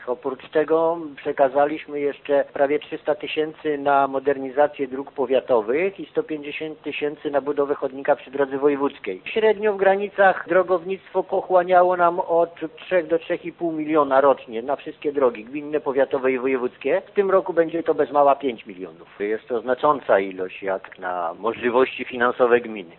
„W tym roku będzie to kwota znacznie większe, bo tylko na drogi gminne przeznaczyliśmy około 3 milionów 800 tysięcy złotych” - mówi Wójt Franciszek Kwiecień: